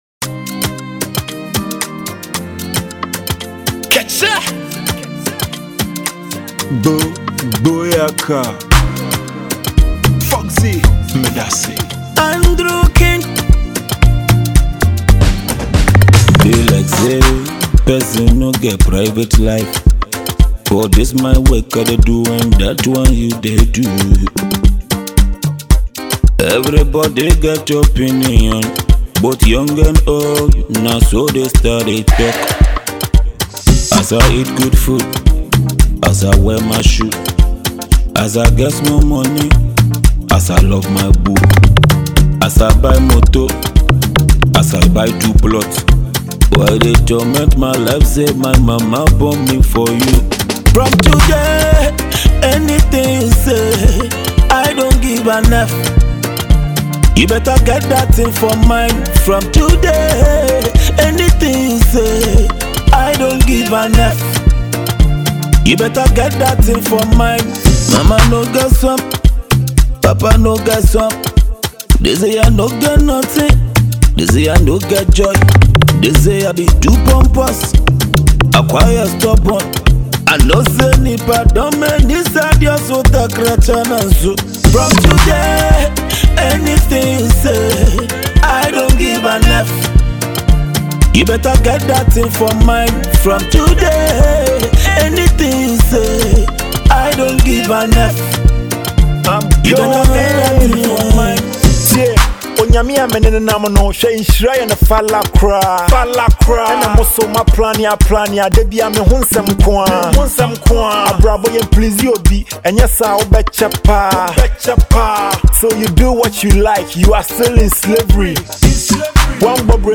a powerful duo artists